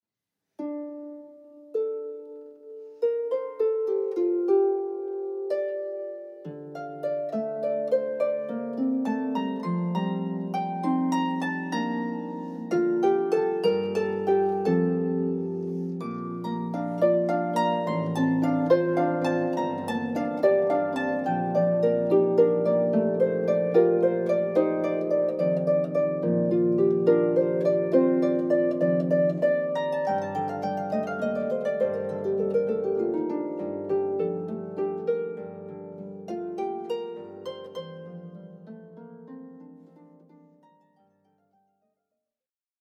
kirchlicher Gesänge und Gebete